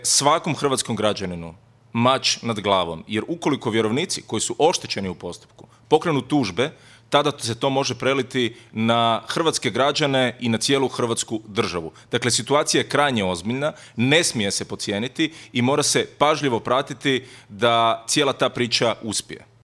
ZAGREB - "Svi su sve znali, zato smo i tražili Marićevu ostavku" rekao je predsjednik SDP-a Davor Bernardić u intervju tjedna Media servisa komentirajući posljednje medijske napise o stanju u Agrokoru.